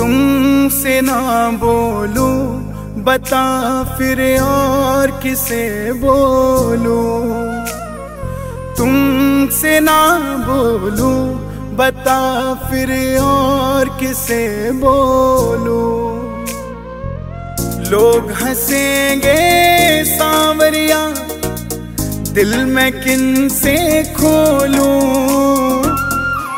In Bhakti